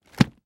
На этой странице собраны звуки капельницы в разных вариантах: от монотонного стекания жидкости до фонового шума больничной палаты.
Прокалывали капельницей раствор